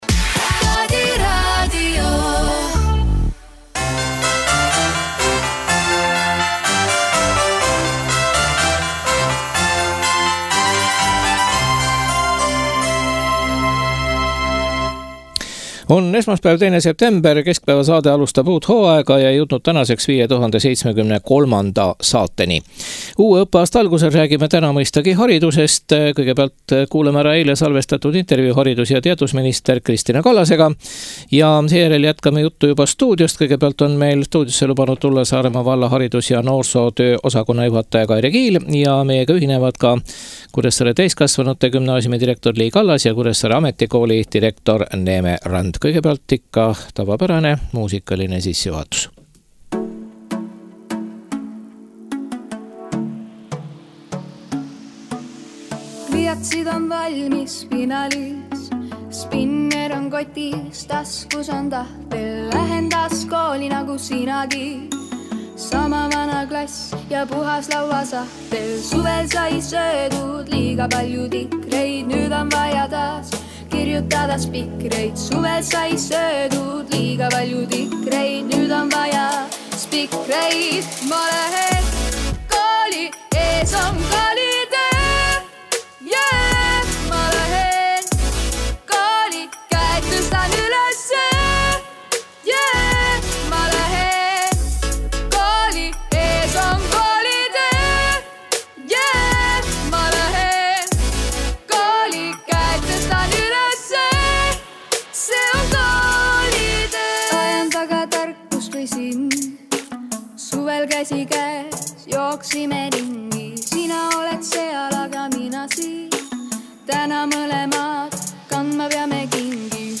Uue õppeaasta alguses räägime mõistagi haridusest. Kuulame ära eile salvestatud intervjuu haridus- ja teadusminister Kritina Kallasega.